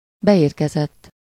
Ääntäminen
Ääntäminen US : IPA : [rɪˈsiːvd] Tuntematon aksentti: IPA : /ɹɪˈsiːvd/ Haettu sana löytyi näillä lähdekielillä: englanti Käännös Ääninäyte Adjektiivit 1. beérkezett Received on sanan receive partisiipin perfekti.